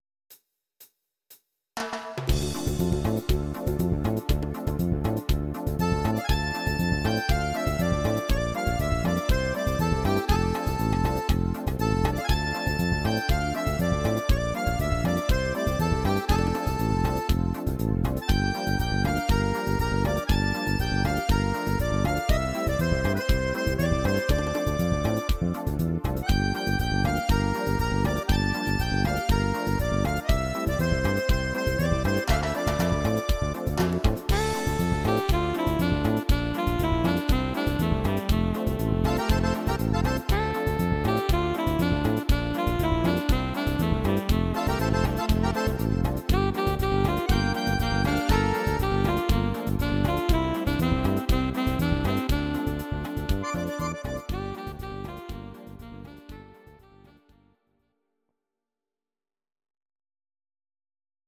Audio Recordings based on Midi-files
Pop, 1980s